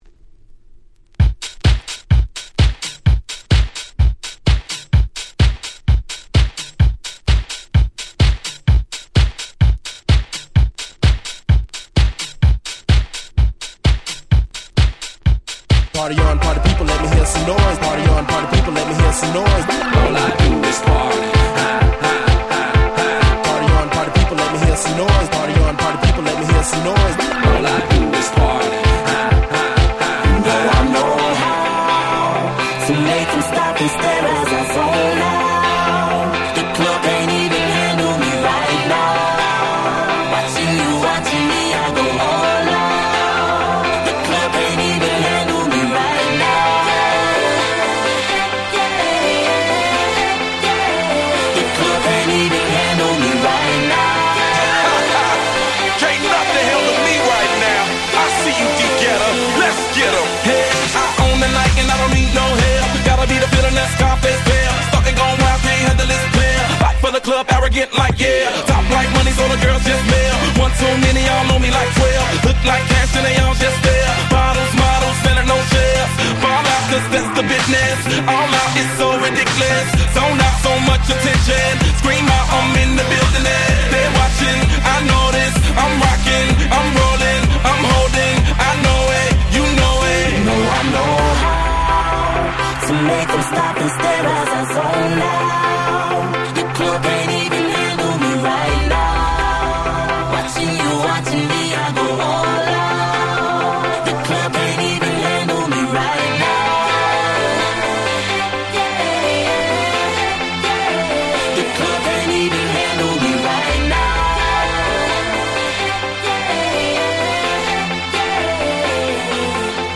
フローライダ デヴィットゲッタ EDM アゲアゲ パリピ 10's